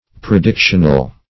Predictional \Pre*dic"tion*al\, a. Prophetic; prognostic.